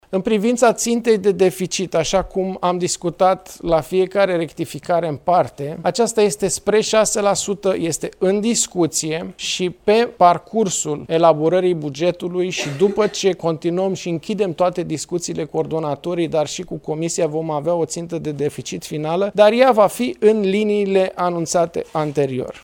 Ministrul Finanțelor, Alexandru Nazare: „În privința țintei de deficit, așa cum am discutat la fiecare rectificare în parte, aceasta este spre 6%”